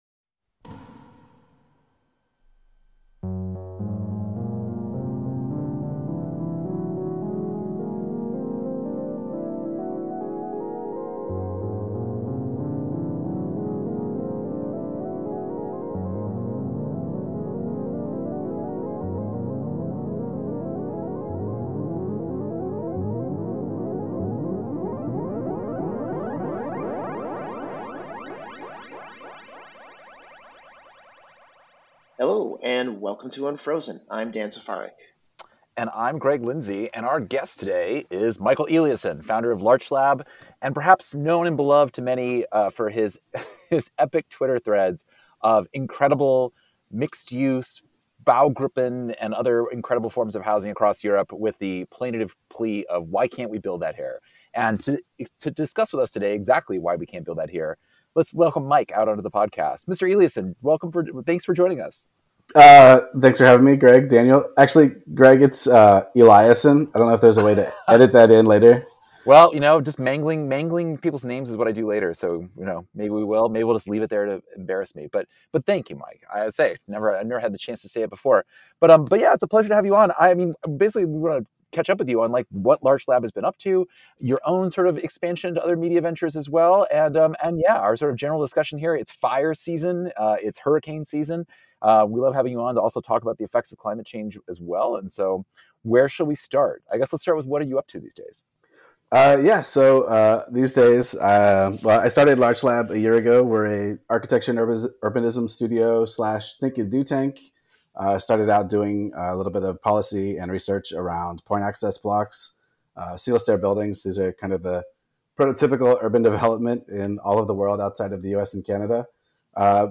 Hear the Unfrozen interview – and then listen to his podcast, Livable Low-Carbon City.